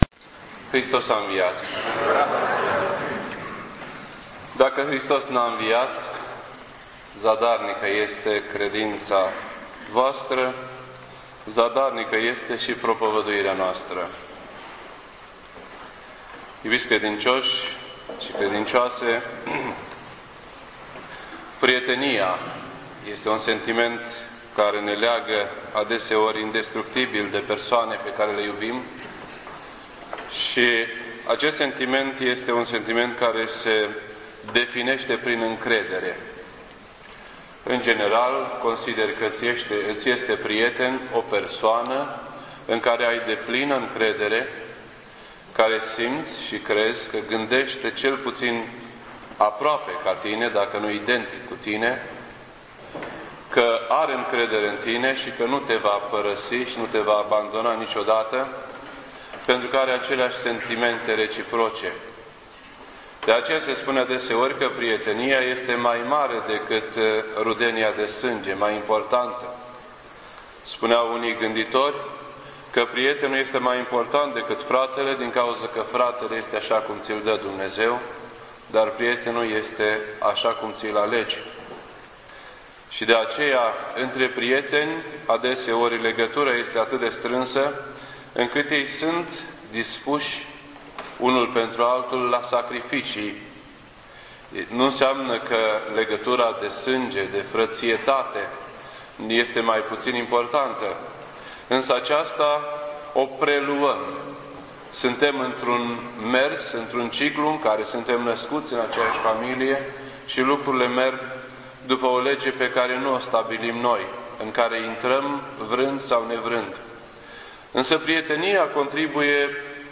2007 at 10:18 AM and is filed under Predici ortodoxe in format audio .